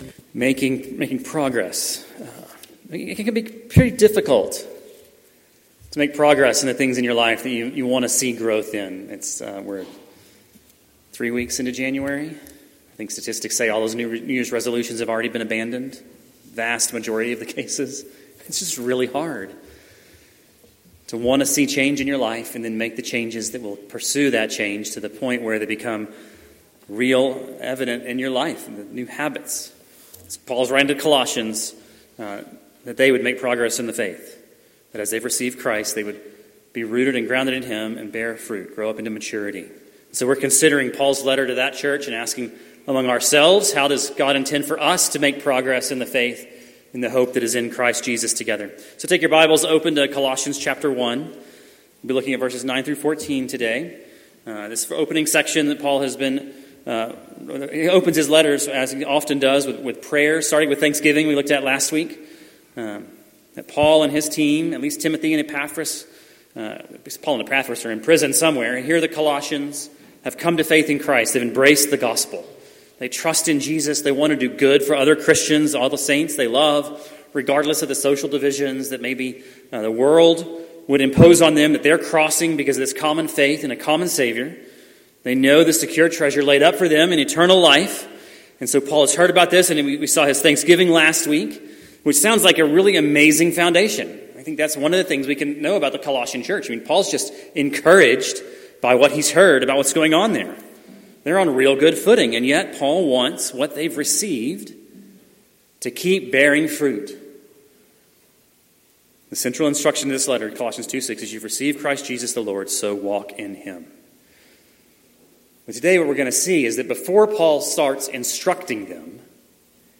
Sunday morning sermons from the First Baptist Church of Wolfforth, Texas.